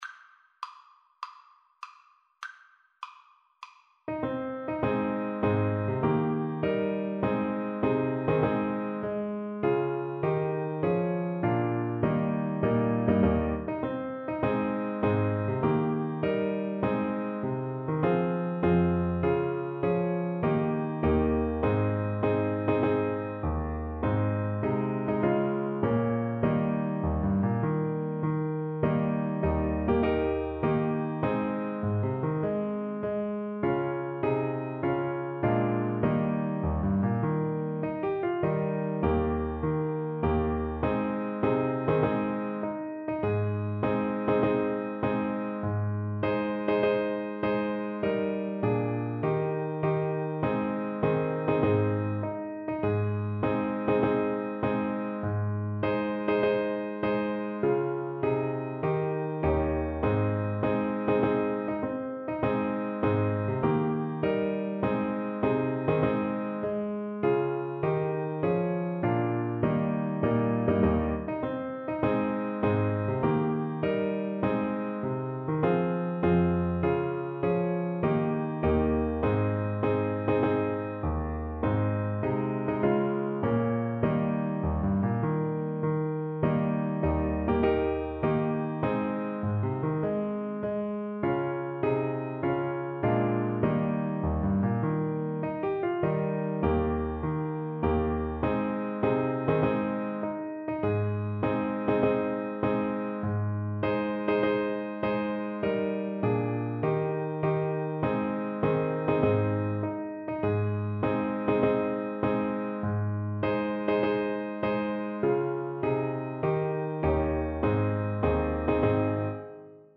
Free Sheet music for Alto Saxophone
Alto Saxophone
Ab major (Sounding Pitch) F major (Alto Saxophone in Eb) (View more Ab major Music for Saxophone )
4/4 (View more 4/4 Music)
March =c.100
C5-Eb6
Traditional (View more Traditional Saxophone Music)